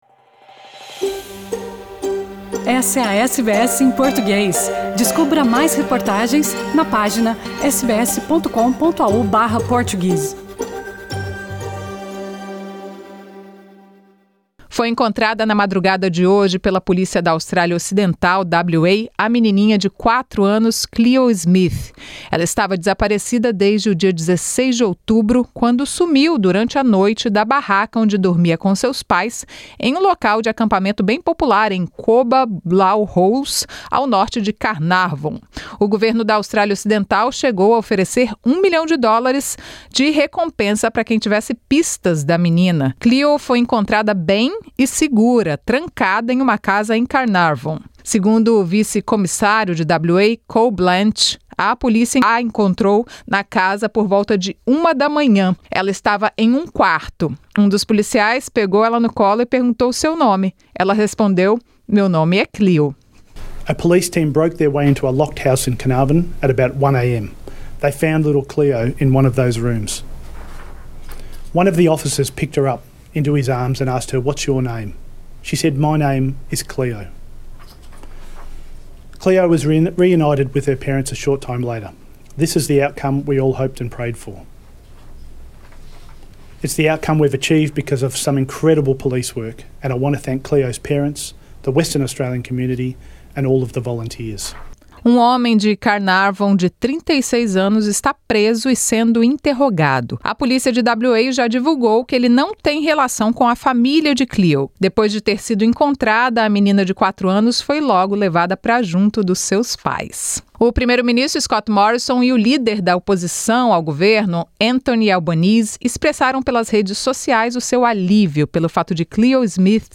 Relações entre França e Austrália estremecidas após Morrison ser acusado de mentir sobre acordo de submarinos e vazar mensagens privadas com Macron. As notícias da Austrália e do mundo da Rádio SBS para esta quarta-feira.